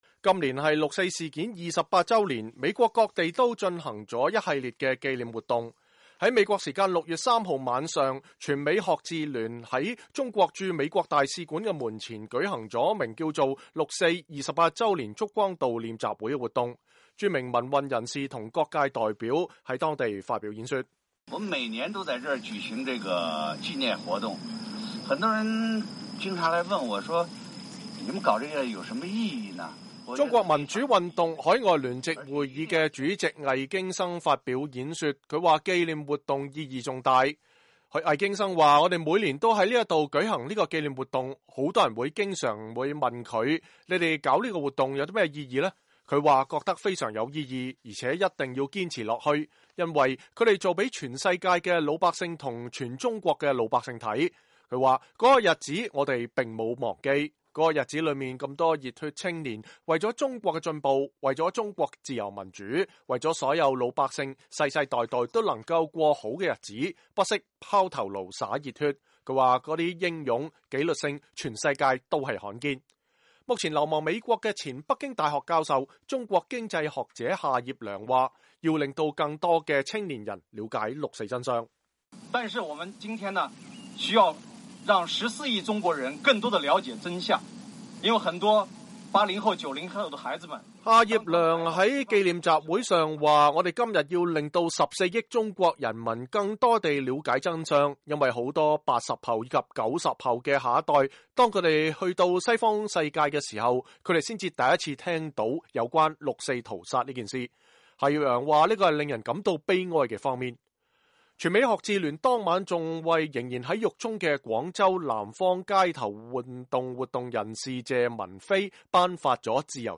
今年是六四事件28周年，美國各地都進行了一系列紀念活動。6月3日晚，全美學自聯在中國駐美大使館的門前進行了名為“六四”28周年燭光追悼會的活動，著名民運人士和各界代表發表演講。